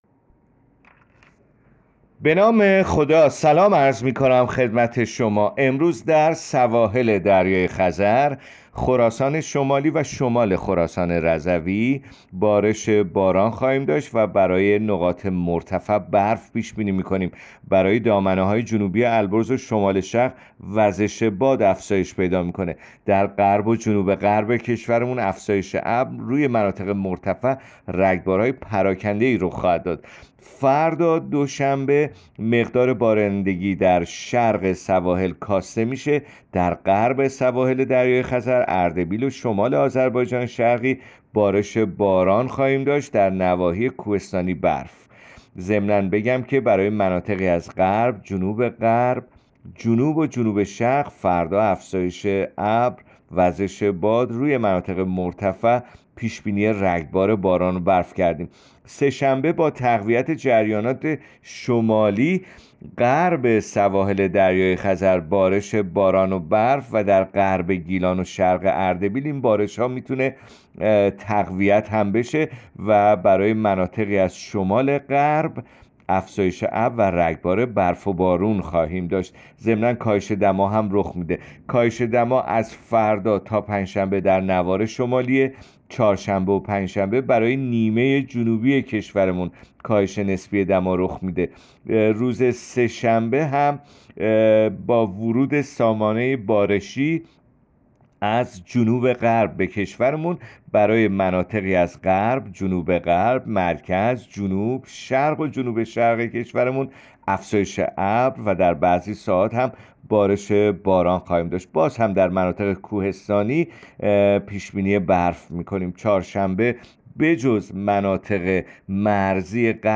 گزارش رادیو اینترنتی پایگاه‌ خبری از آخرین وضعیت آب‌وهوای ۹ دی؛